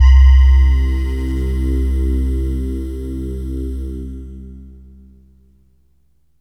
SYNTH GENERAL-1 0006.wav